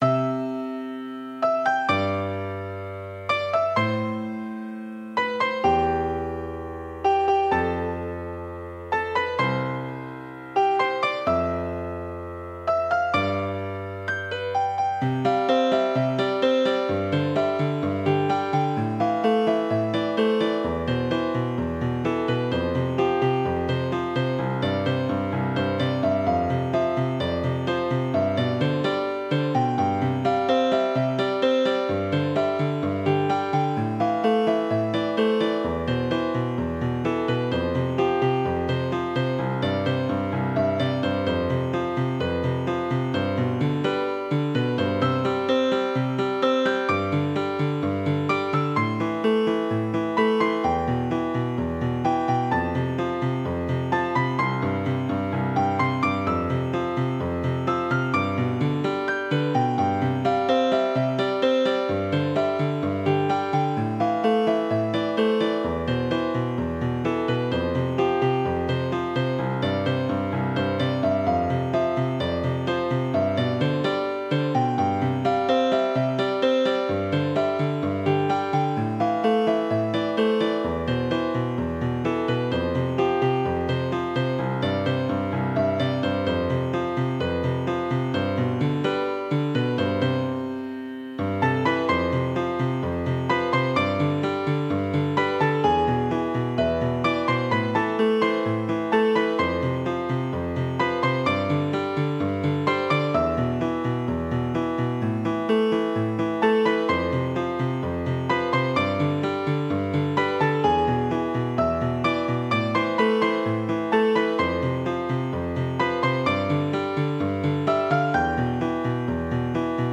始めてカノン進行を使った曲はこの曲だったりします